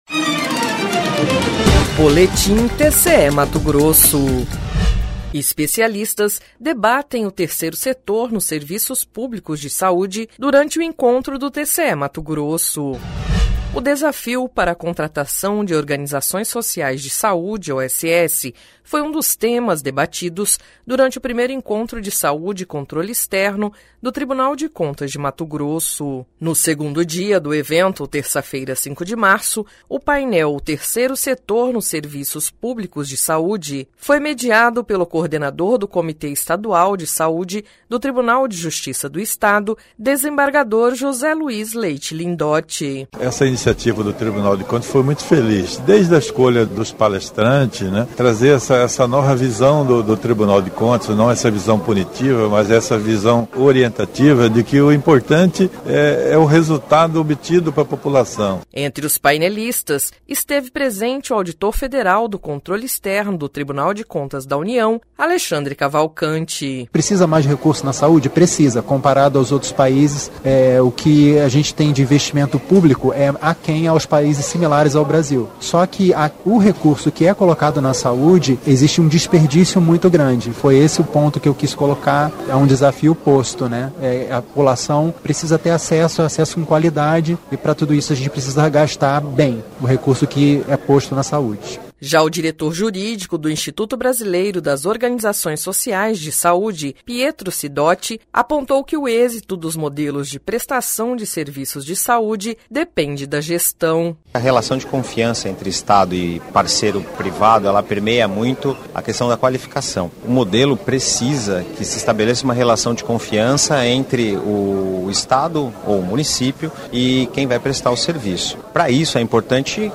Sonora: José Luiz Leite Lindote - desembargador
Sonora: Bruno Maia – conselheiro do TCM-RJ